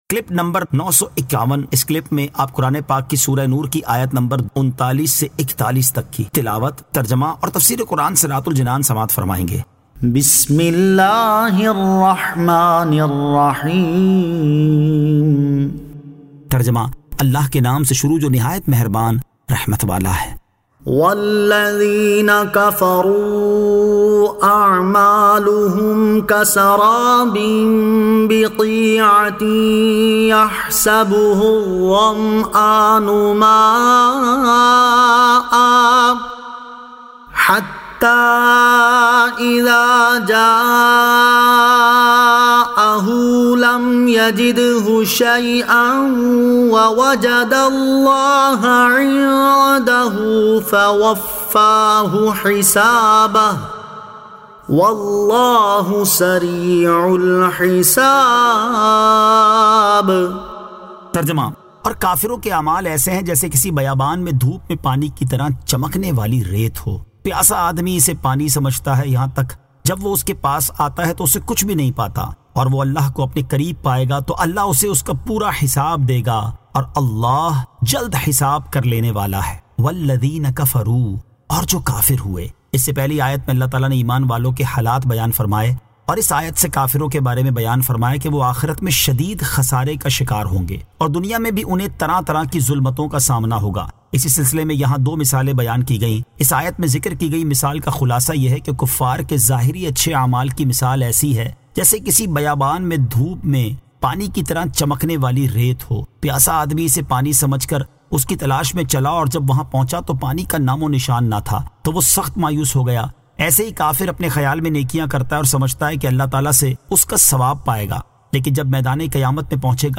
Surah An-Nur 39 To 41 Tilawat , Tarjama , Tafseer